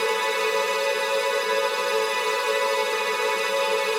GS_TremString-B7.wav